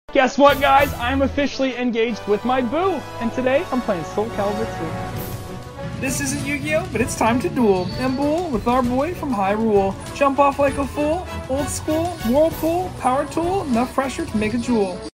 Soulcalibur II GameCube Game sound effects free download